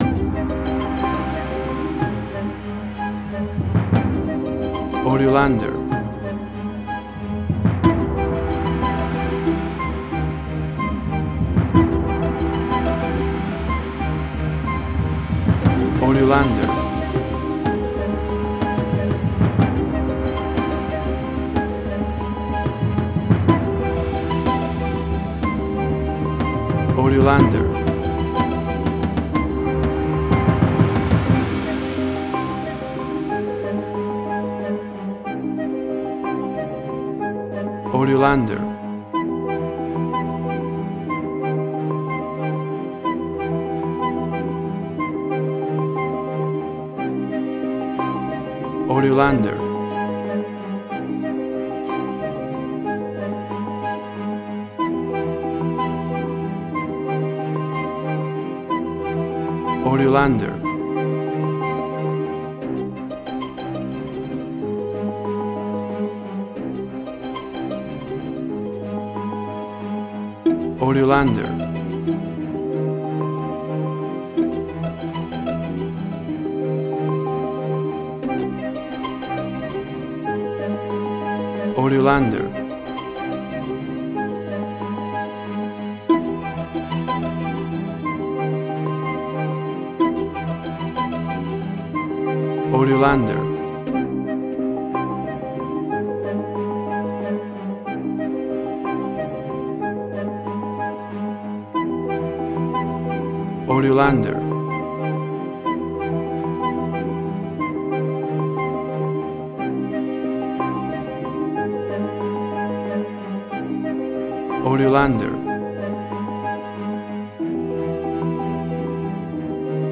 Moving underwater style with flute and percusion
Tempo (BPM): 92